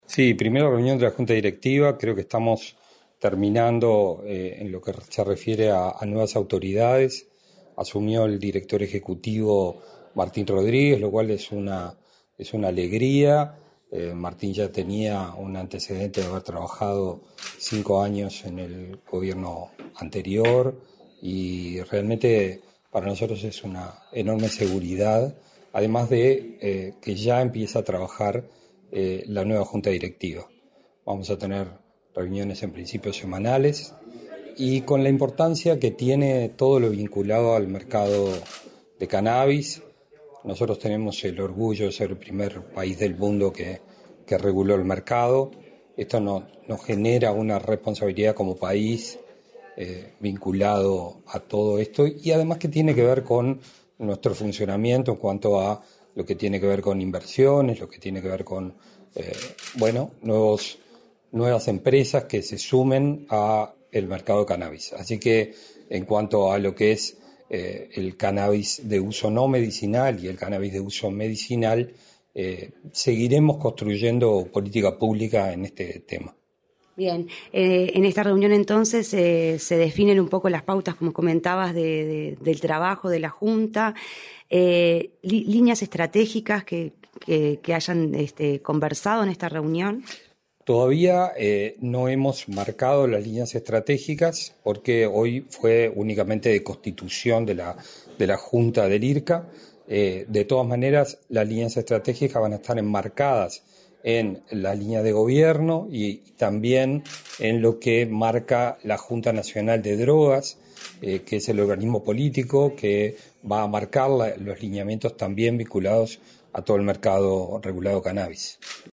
Entrevista al secretario general de la Junta Nacional de Drogas, Gabriel Rossi
Entrevista al secretario general de la Junta Nacional de Drogas, Gabriel Rossi 10/04/2025 Compartir Facebook X Copiar enlace WhatsApp LinkedIn El secretario general de la Junta Nacional de Drogas, Gabriel Rossi, dialogó con Comunicación Presidencial, durante la asunción de las nuevas autoridades del Instituto de Regulación y Control del Cannabis (IRCCA), cuyo director ejecutivo será Martín Rodríguez.